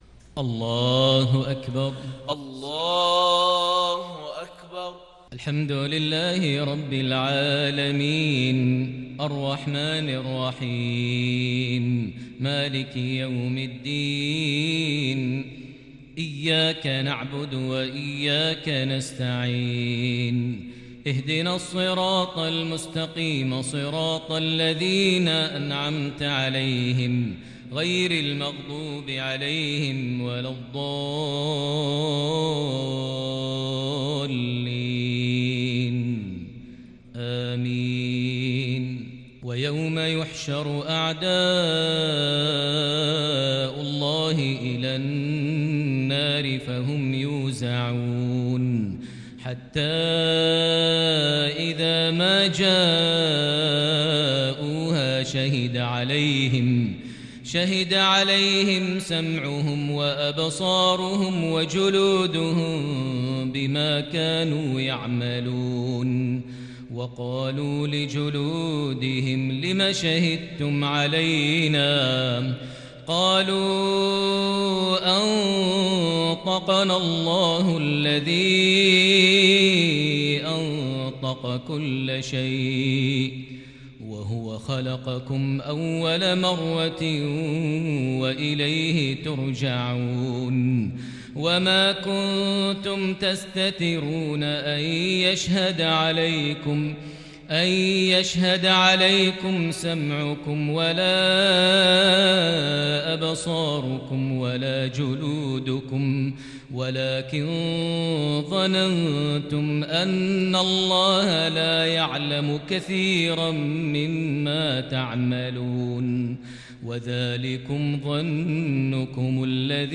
صلاة العشاء للشيخ ماهر المعيقلي 30 صفر 1442 هـ